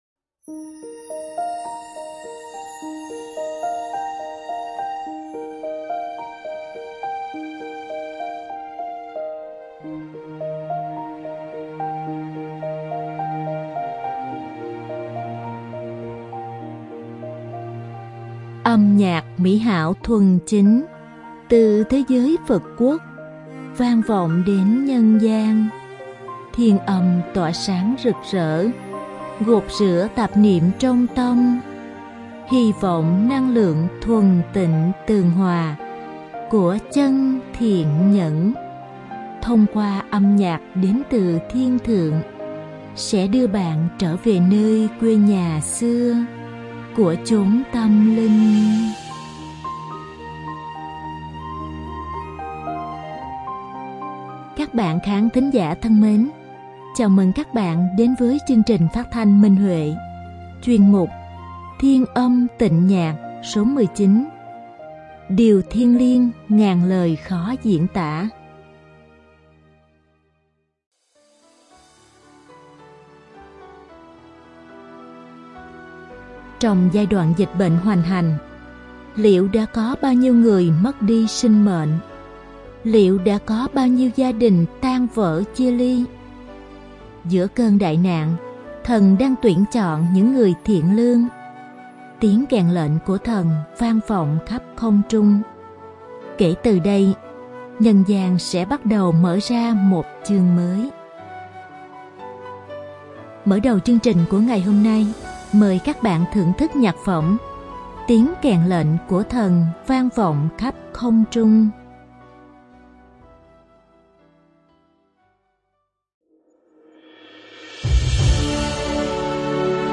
Đơn ca nữ